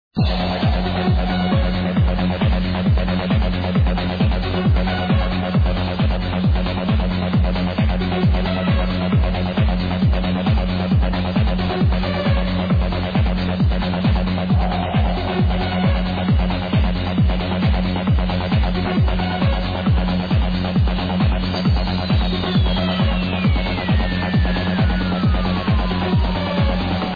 oh shit, this one sounds really familiar, that bassline